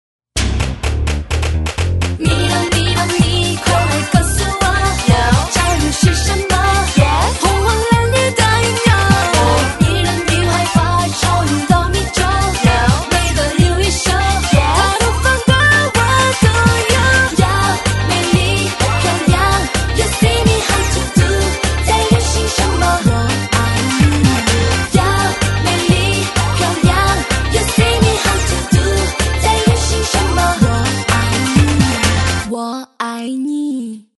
韩国歌手
编曲采用了时下欧美乐坛最主流电子音色，复古的House鼓点，以及酷炫的人声电音效果。